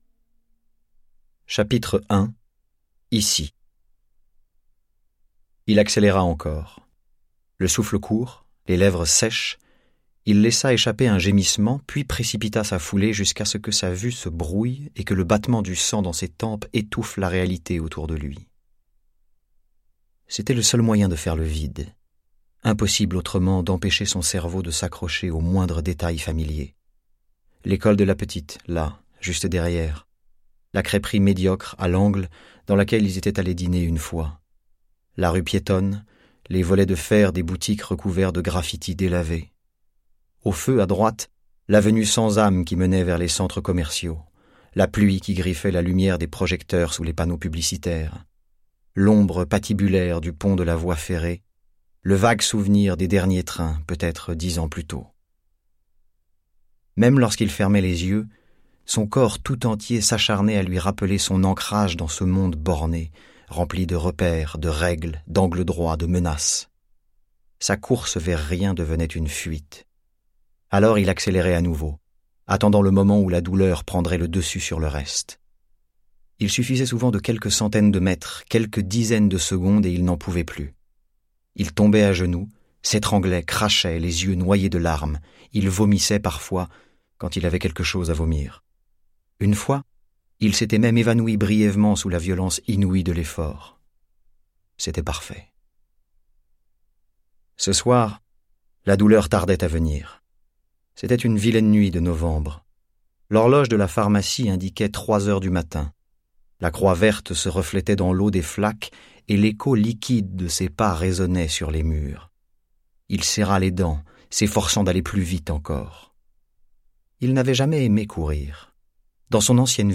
Click for an excerpt - Ceux qui restent de Jean Michelin